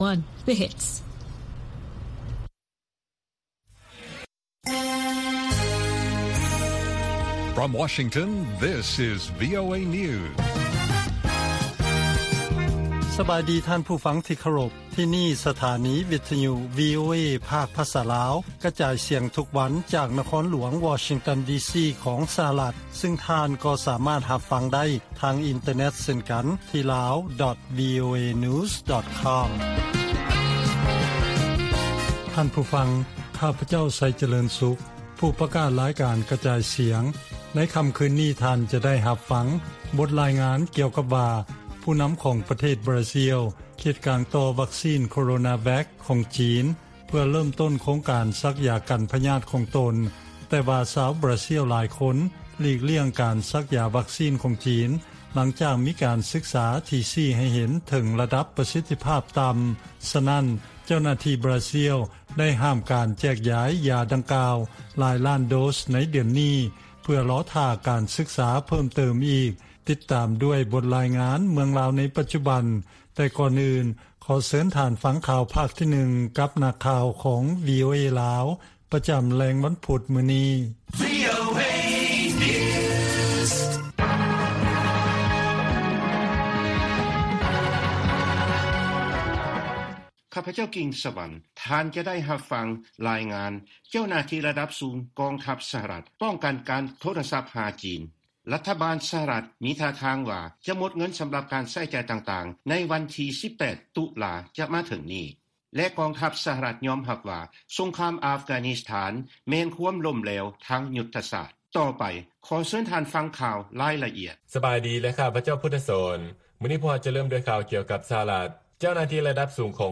ວີໂອເອພາກພາສາລາວ ກະຈາຍສຽງທຸກໆວັນ. ຫົວຂໍ້ຂ່າວສໍາຄັນໃນມື້ນີ້ມີ: 1) ການລະບາດຂອງໂຄວິດ-19 ແລະແຮງງານທີ່ມີຄຸນນະພາບຕໍ່າ ເຮັດໃຫ້ອັດຕາ ການຫວ່າງງານຢູ່ລາວ ເພີ້ມຂຶ້ນເປັນ 25 ເປີເຊັນ.